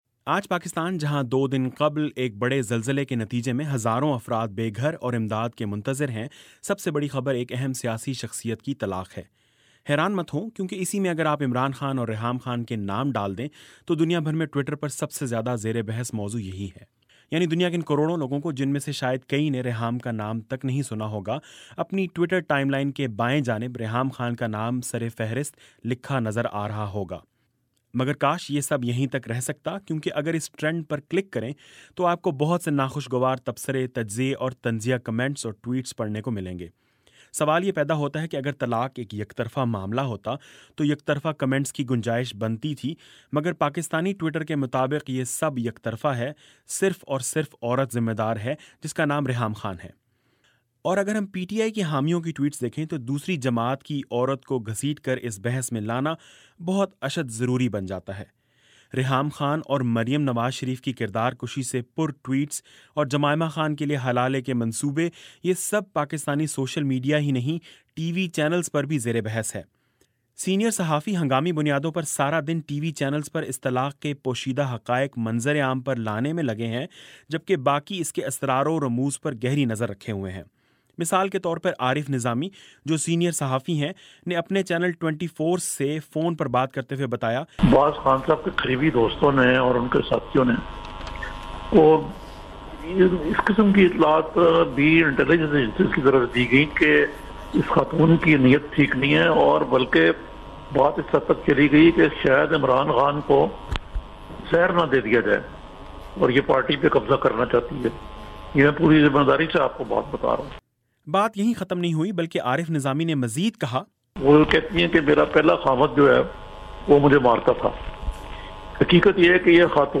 یہ رپورٹ اسلام آباد سے بھجوائی ہے